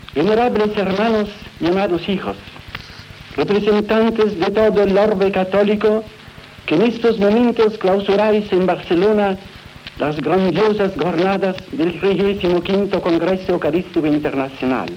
Paraules del Sant Pare Pius XII amb motiu de la clausura del XXV Congrés Eucarístic Internacional que es va fer a Barcelona.